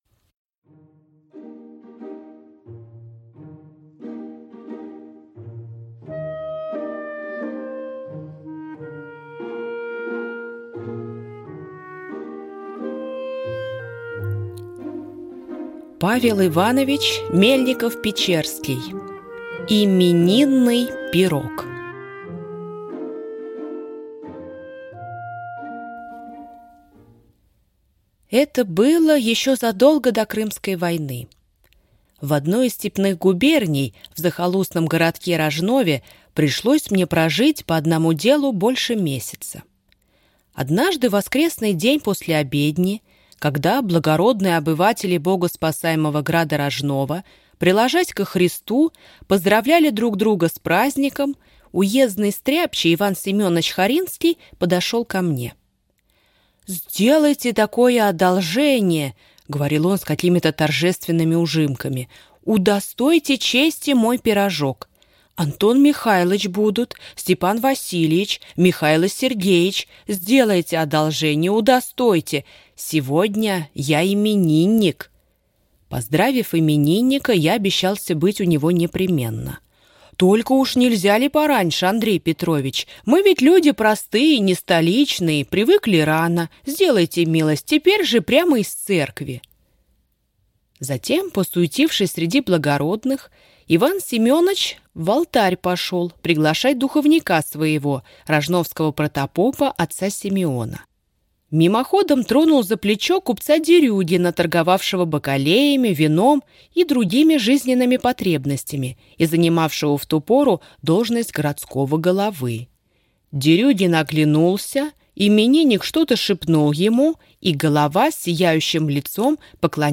Аудиокнига Именинный пирог | Библиотека аудиокниг